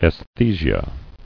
[aes·the·sia]